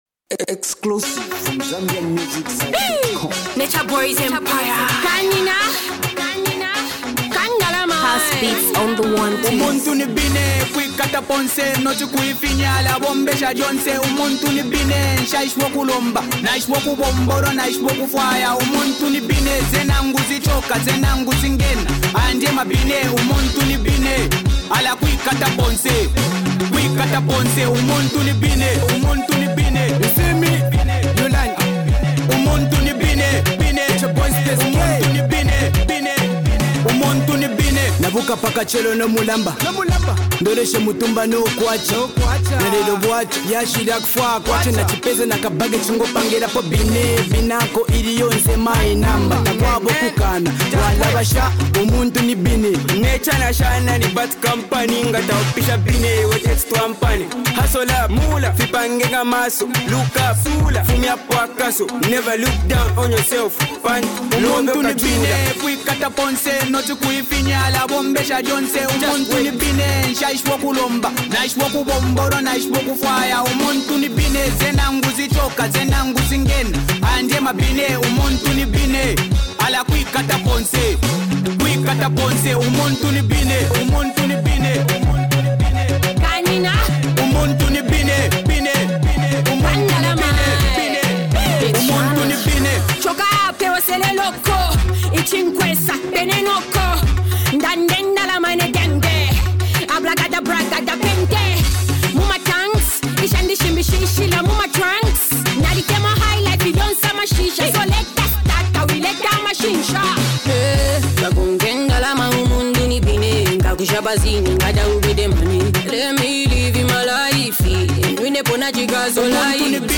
Upcoming duo music group